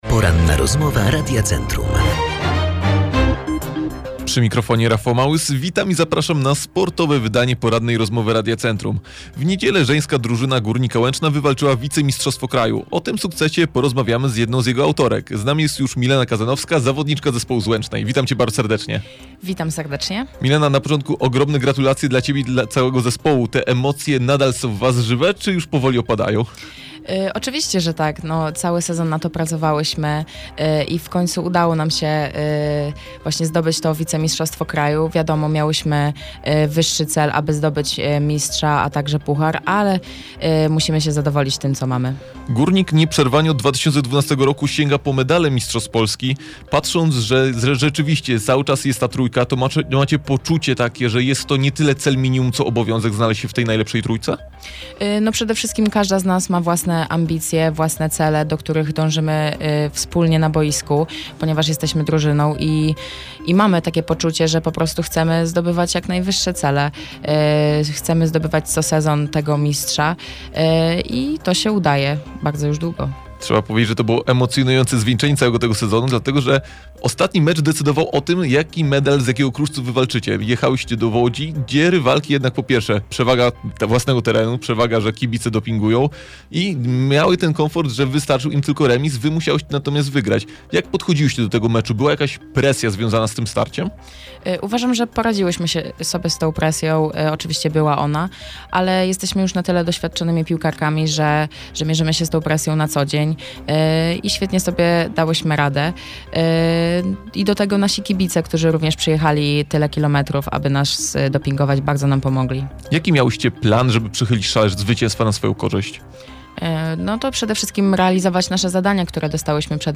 Cala-rozmowa.mp3